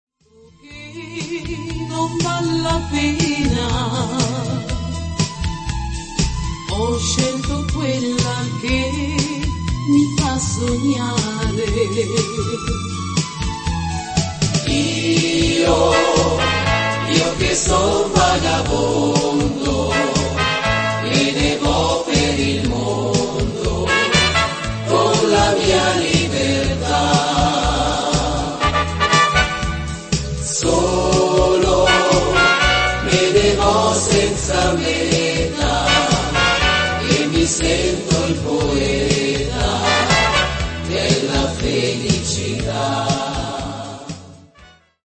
moderato